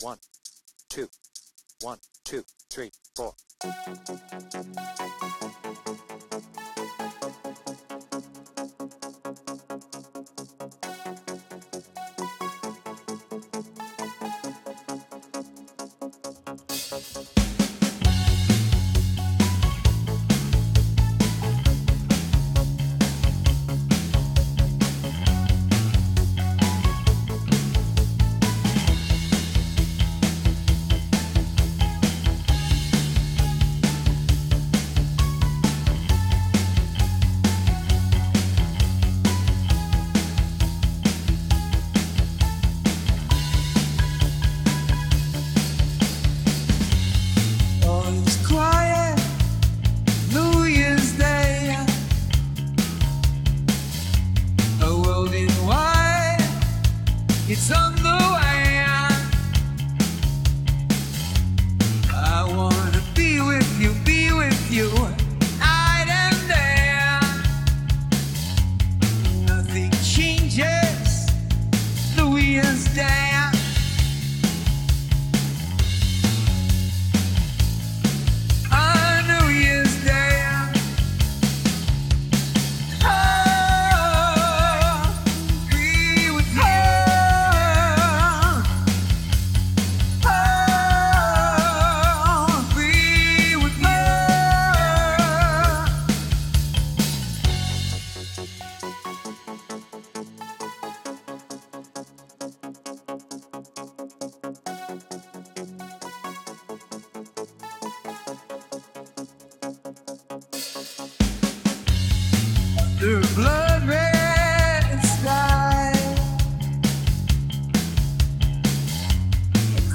BPM : 133
Tuning : D
With vocals
Based on the I+E 2018 Tour